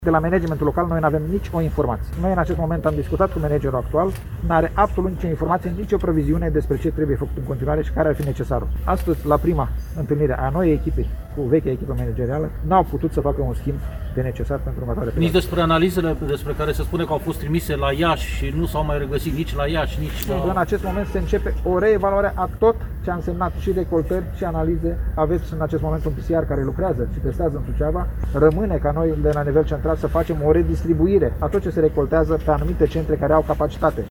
Ministrul Sănătăţii, Nelu Tătaru, a declarat, astăzi, la Suceava, că noua echipă managerială a Spitalului Judeţean de Urgenţă va reorganiza activitatea instiţuţiei sanitare şi evalua starea de sănătate a medicilor suceveni, care vor fi rechemaţi la muncă în momentul în care starea de sănătate le va permite.